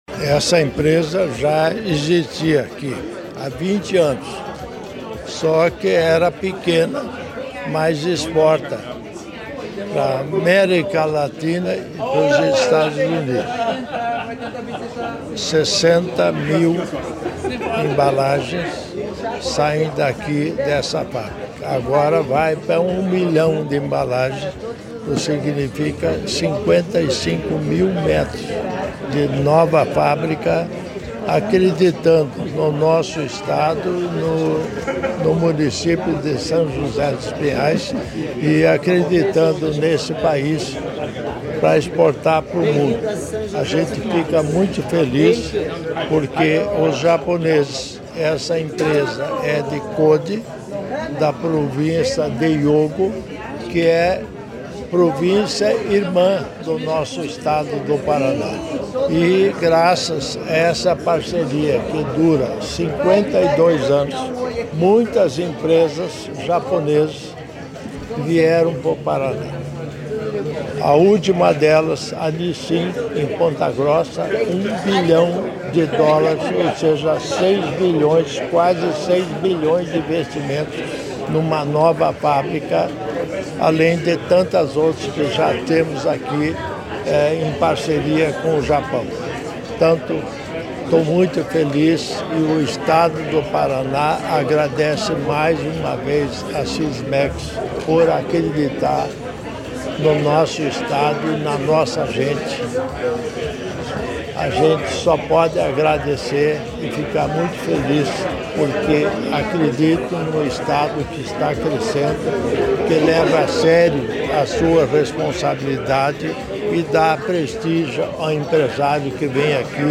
Sonora do vice-governador Darci Piana sobre o anúncio da nova sede Sysmex em São José dos Pinhais